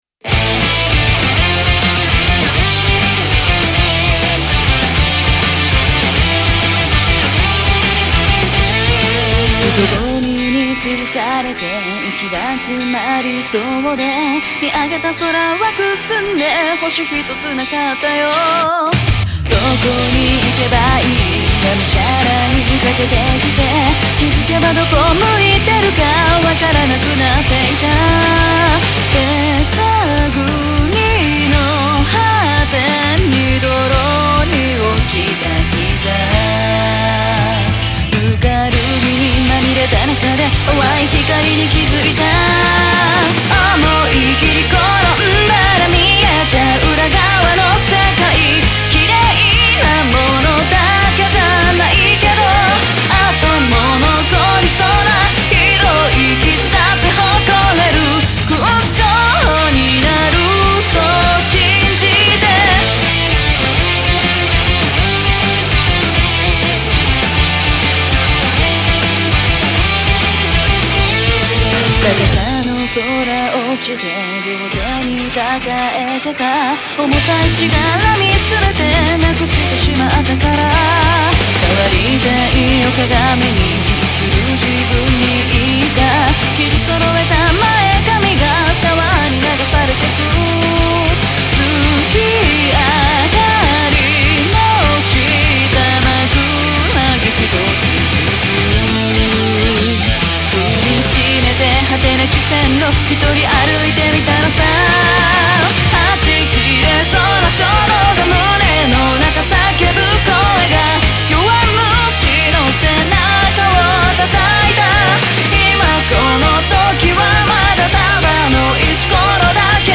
疾走感のあるギターロック。